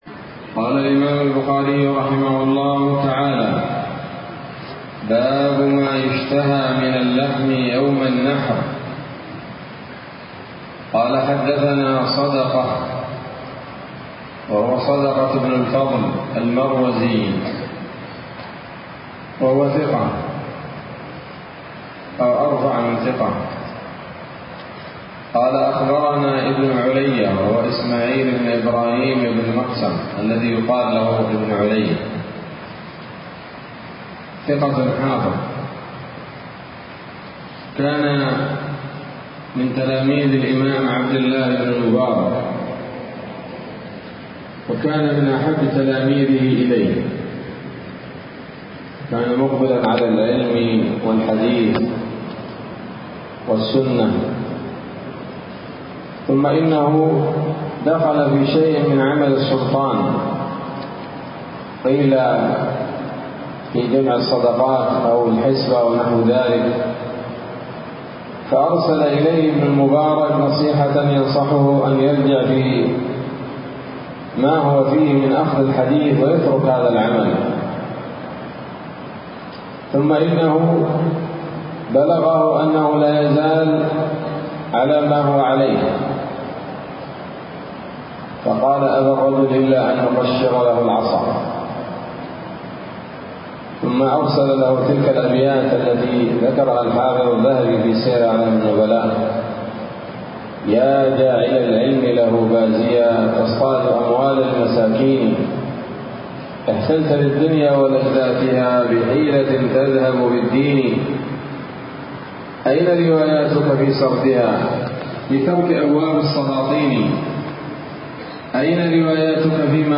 الدرس الرابع من كتاب الأضاحي من صحيح الإمام البخاري